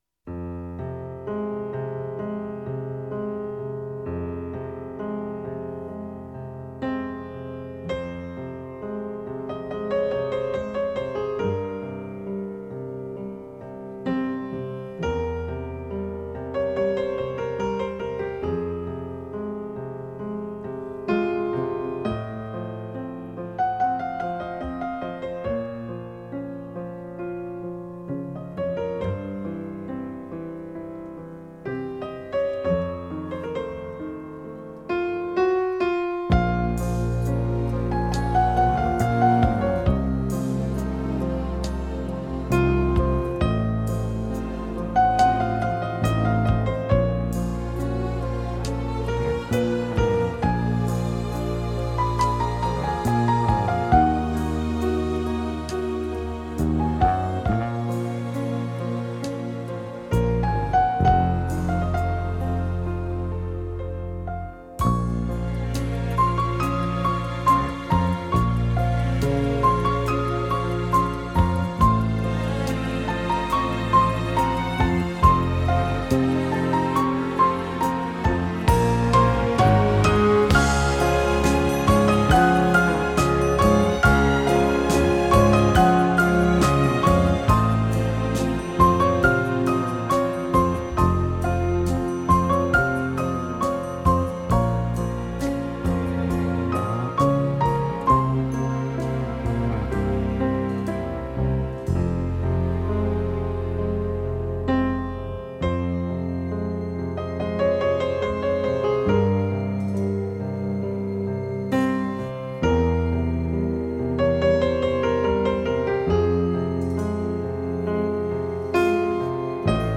2周前 纯音乐 4